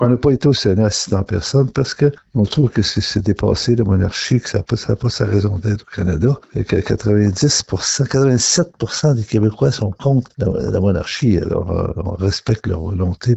Le député de la circonscription de Bécancour-Nicolet-Saurel-Alnôbak a apporté des précisions.